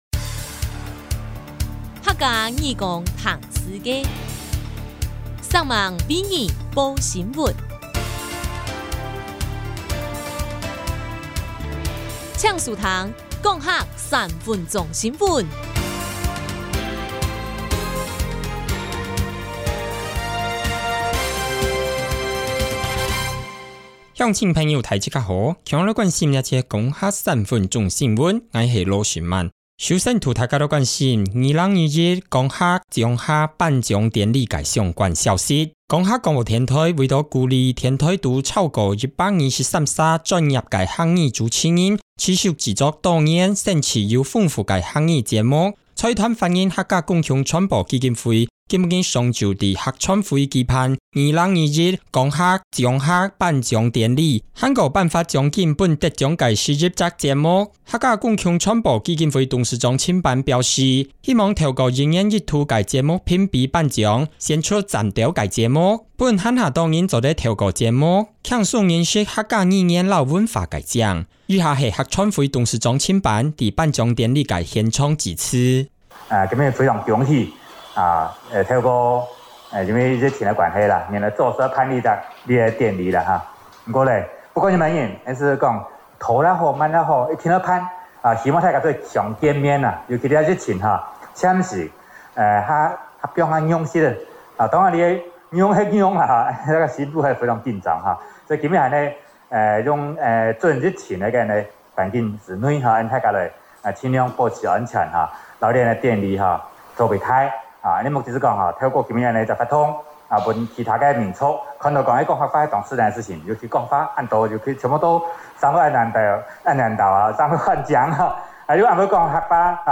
財團法人客家公共傳播基金會今（11）日上午在桃園青埔舉辦「2021講客 Gongˋ Hagˋ 獎客」頒獎典禮並頒發獎金予獲獎的11個節目。
0811頒獎典禮.mp3